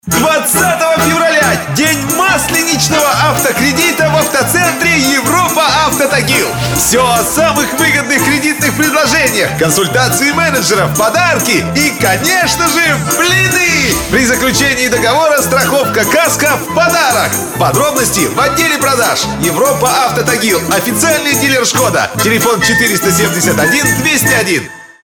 Тракт: Микрофон AT 4060 - Преамп Universal Audio 710 - symetrix 528e - Echo audiofire8